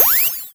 doorOpen_002.ogg